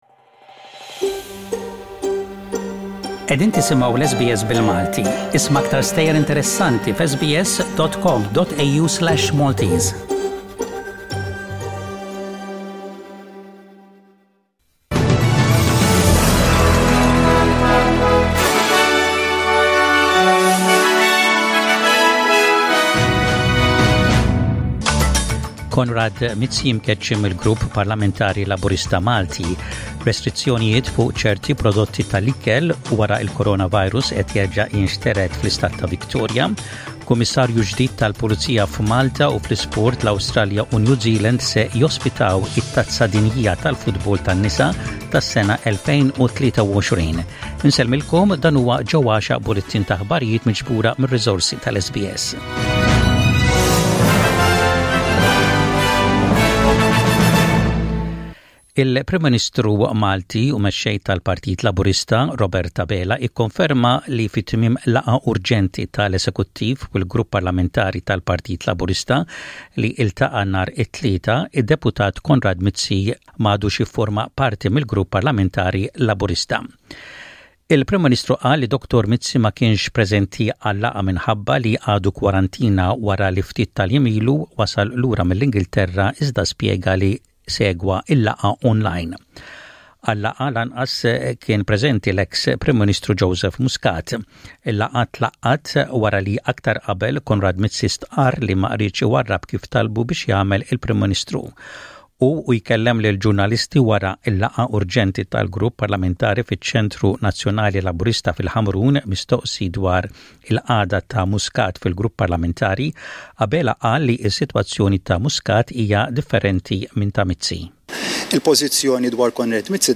SBS Radio | Maltese News: 26/06/20